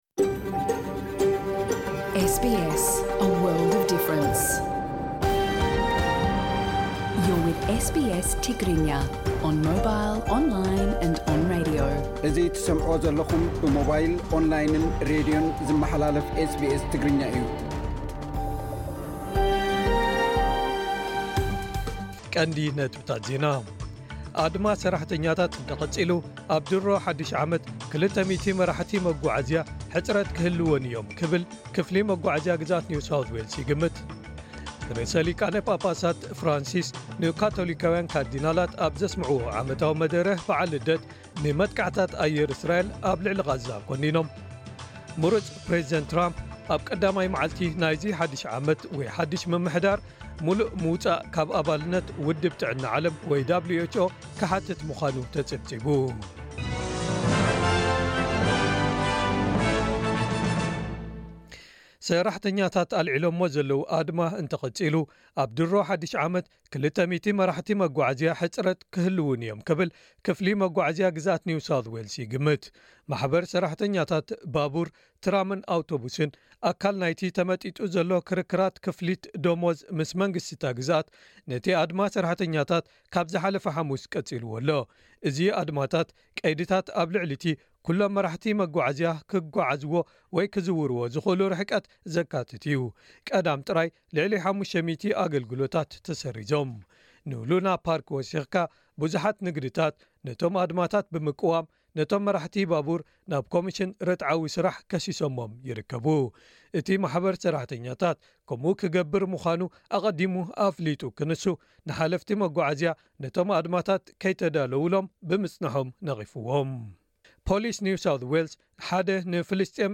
ዕለታዊ ዜና ኤስቢኤስ ትግርኛ (23 ታሕሳስ 2024)